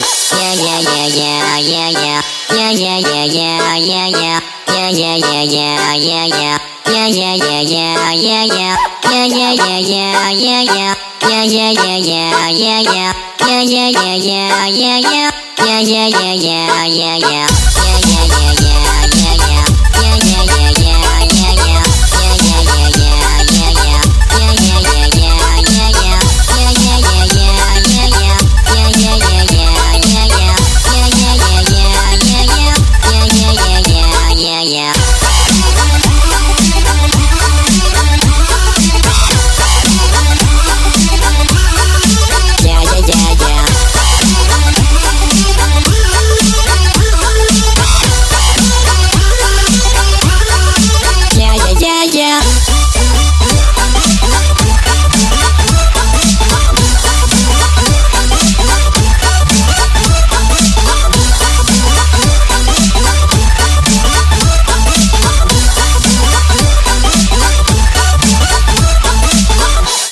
• Качество: 140, Stereo
веселая музыка
Техно